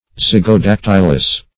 Zygodactylous \Zyg`o*dac"tyl*ous\